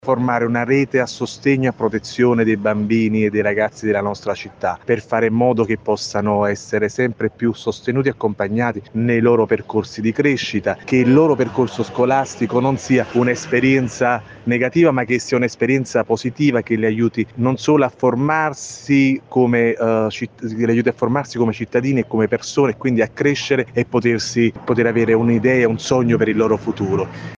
In occasione della giornata mondiale degli Oceani la presentazione dei dati della campagna “Clean Up The Med” promossa da Legambiente. Il servizio